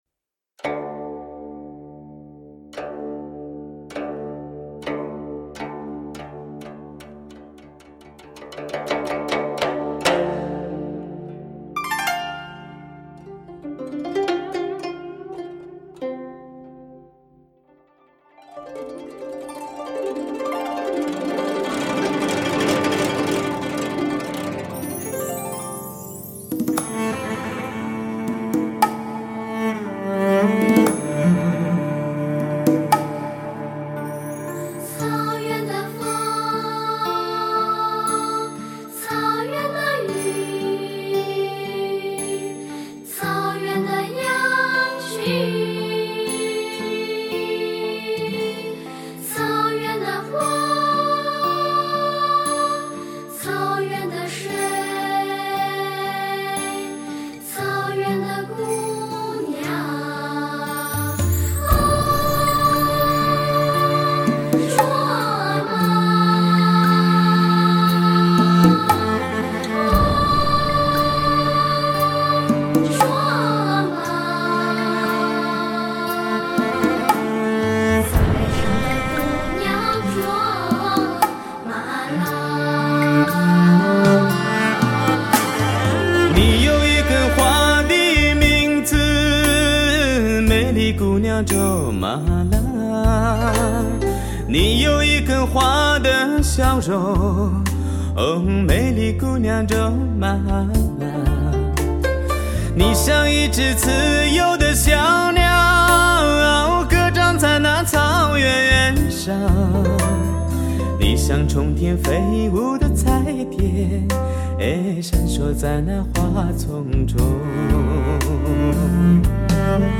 母带级音质 发烧新体验
全面恢复黑胶唱片的空气感和密度感
低音强劲有力，中音清晰丰满，高音柔和圆润，精确的乐器定位，清晰的人声，层次分明，声场辽阔。